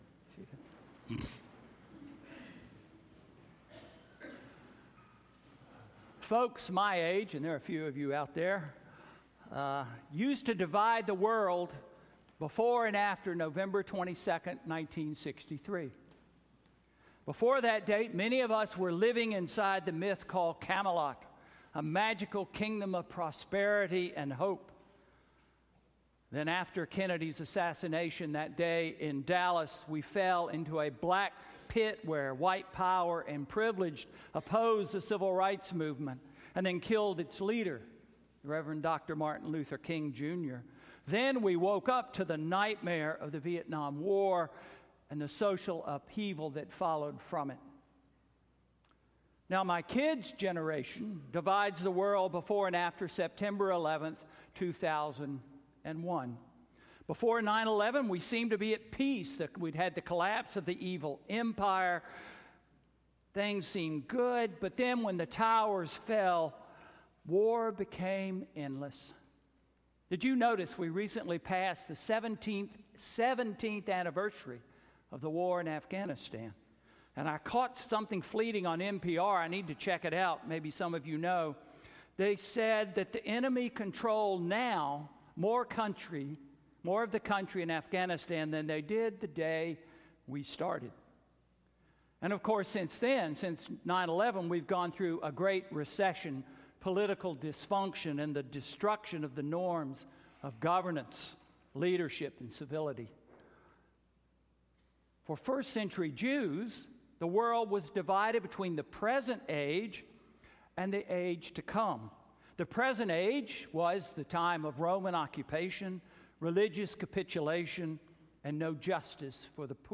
Sermon–The Age to Come Starts Now–October 14, 2018